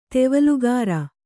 ♪ tevalugāra